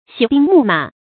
洗兵牧馬 注音： ㄒㄧˇ ㄅㄧㄥ ㄇㄨˋ ㄇㄚˇ 讀音讀法： 意思解釋： 洗擦兵器，喂養戰馬。指做好作戰準備。